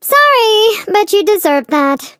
flea_kill_vo_05.ogg